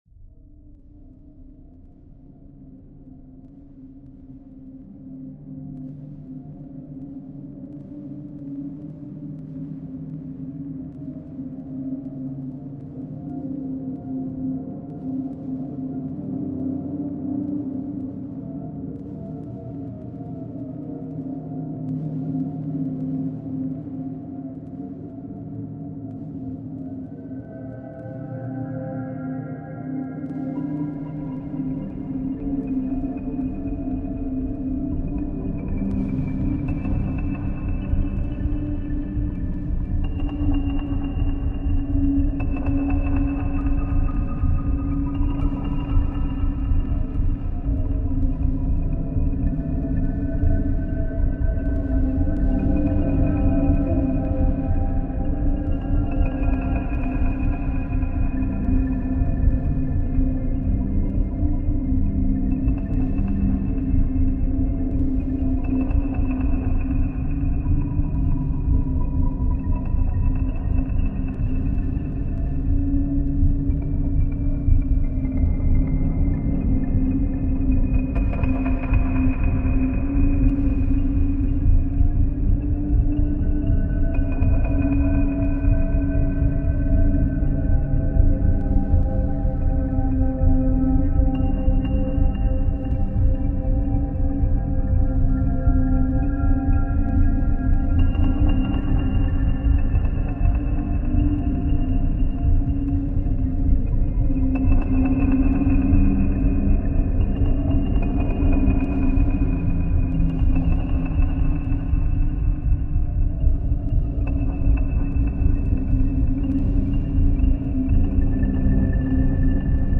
Varanasi river ceremony reimagined